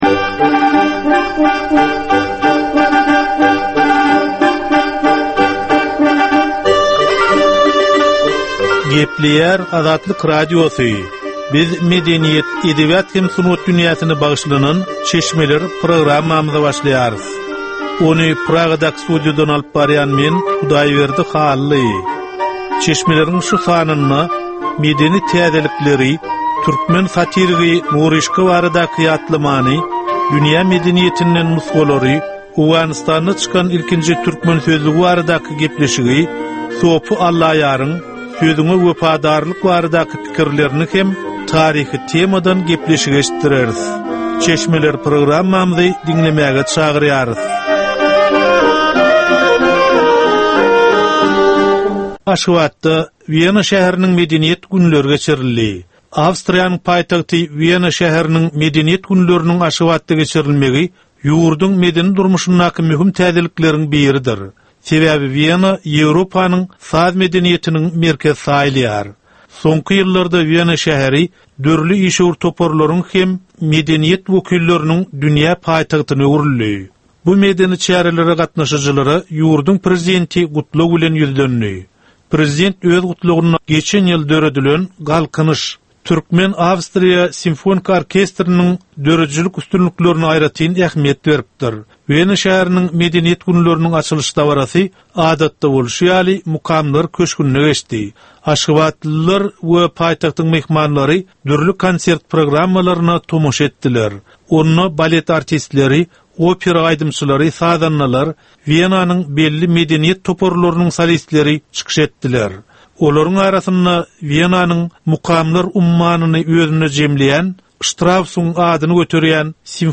Edebi, medeni we taryhy temalardan 25 minutlyk ýörite gepleşik.